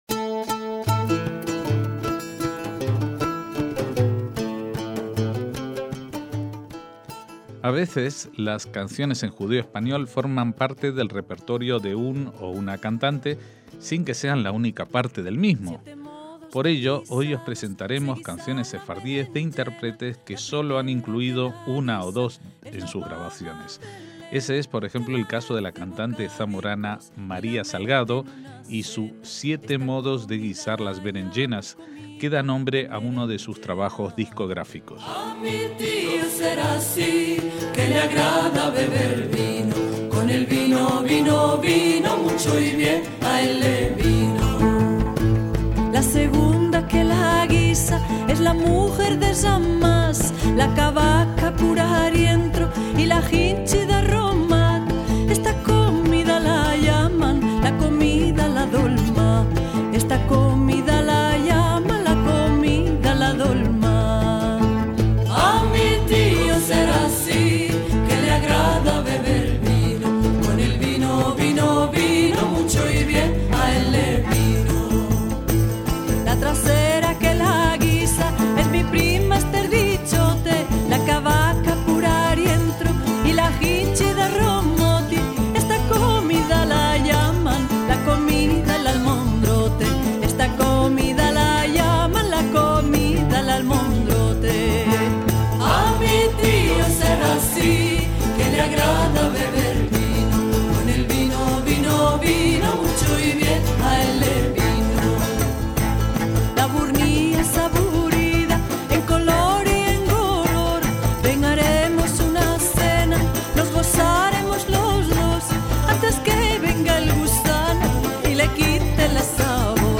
MÚSICA SEFARDÍ - Son muchos los músicos que, sin ser especialistas en el tema, han incluido algún tema sefardí o un par en su repertorio.